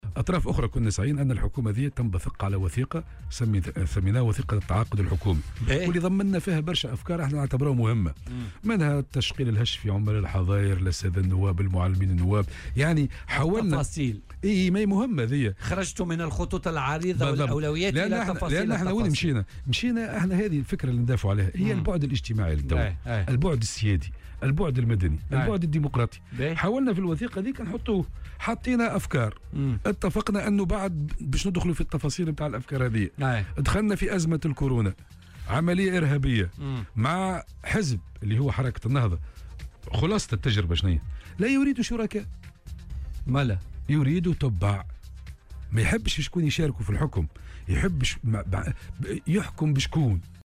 وأضاف في مداخلة له اليوم في برنامج "بوليتيكا" أنهم تقدموا في السابق بمقترحات، لتعزيز البعد الاجتماعي والسيادي والمدني للدولة وإعداد "وثيقة تعاقد الحكومة"، تتضمن العديد من النقاط الهامة، لكن لم يقع تنفيذها.